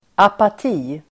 Ladda ner uttalet
Uttal: [apat'i:]